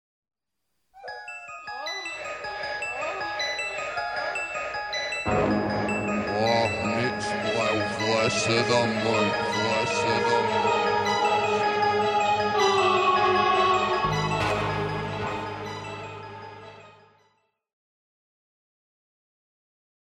Genere: heavy metal